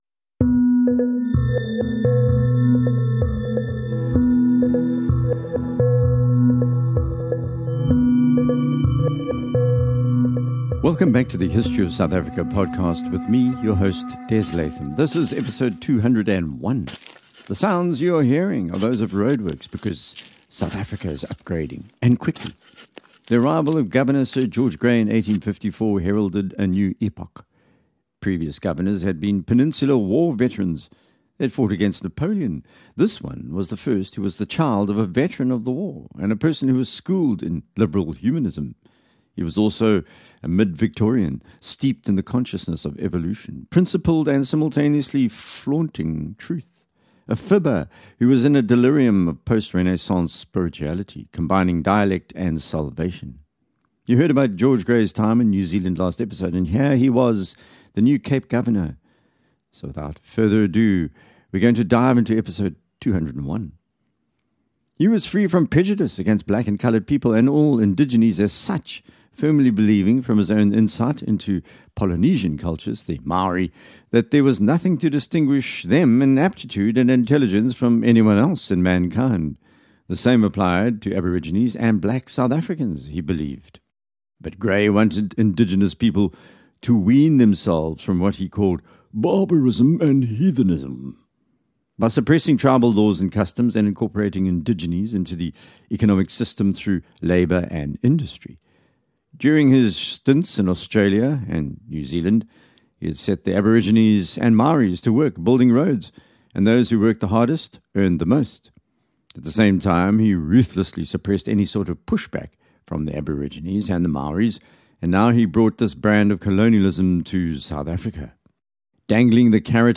A series that seeks to tell the story of the South Africa in some depth. Presented by experienced broadcaster/podcaster